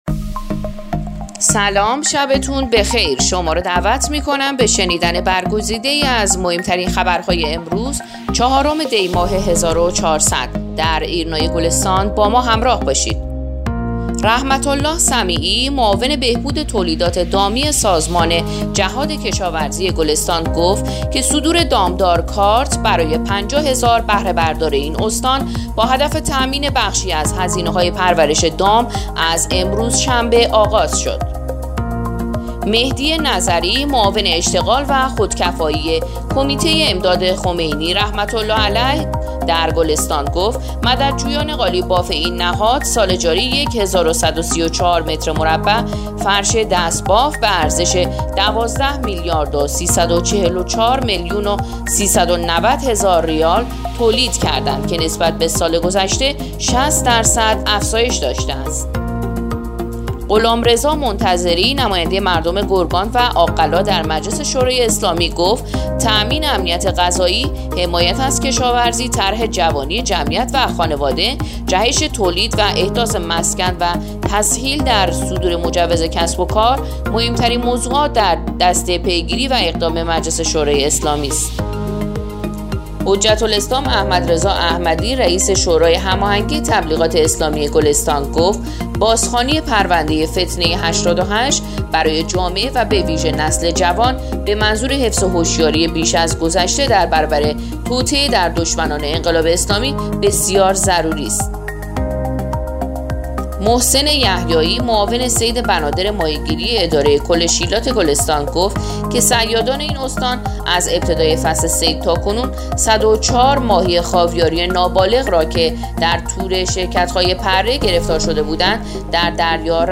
پادکست/ اخبار شبانگاهی چهارم دی ماه ایرنا گلستان